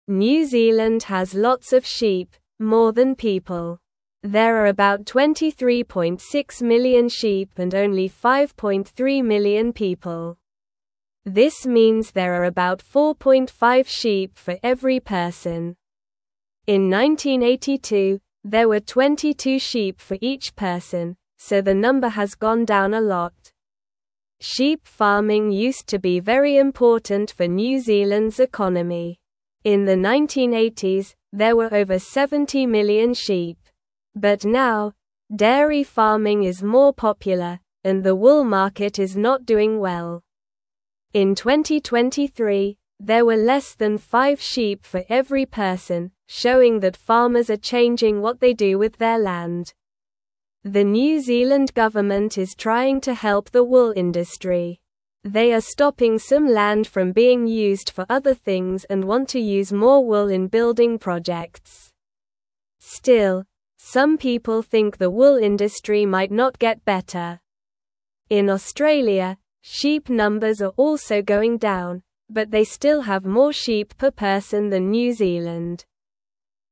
Slow
English-Newsroom-Lower-Intermediate-SLOW-Reading-New-Zealands-Sheep-Numbers-Are-Getting-Smaller.mp3